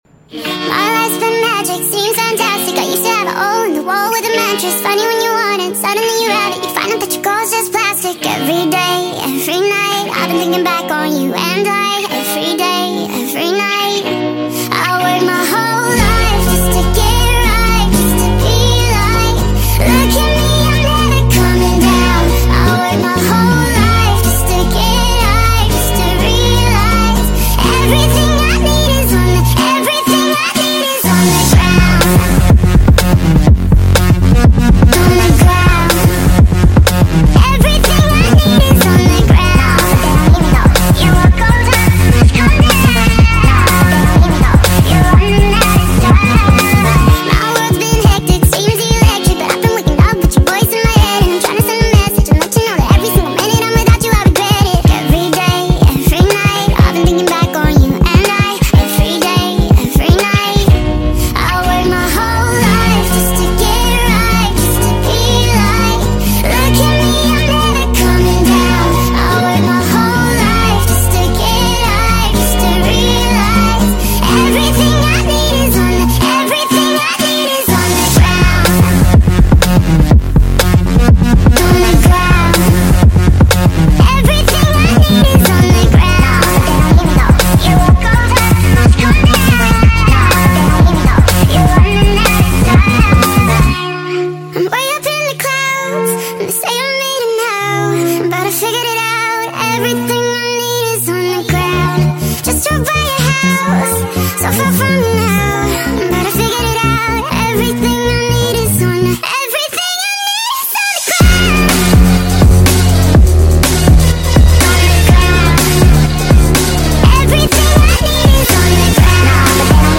speed up ver. { Kpop speed up song